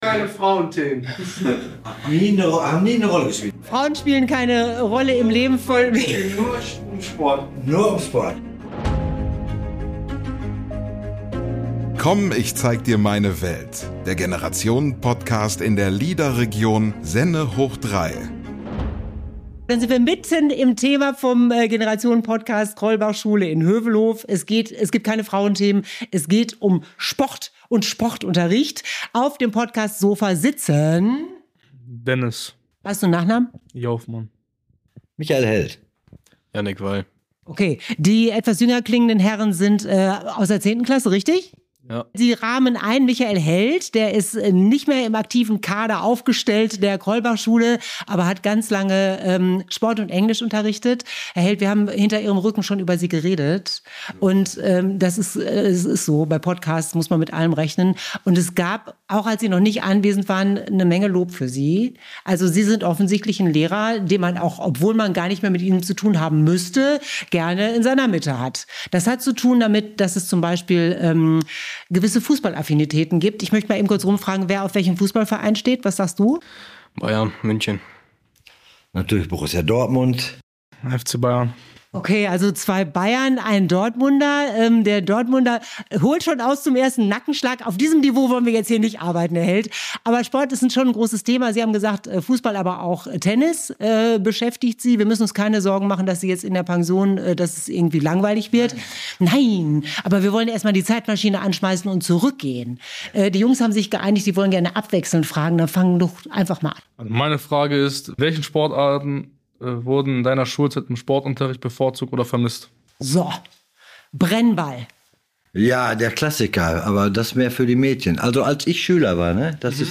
In der heutigen Episode ist der Podcast zu Gast an der Krollbachschule in Hövelhof.